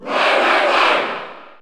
Category: Crowd cheers (SSBU) You cannot overwrite this file.
Roy_Koopa_Cheer_French_NTSC_SSBU.ogg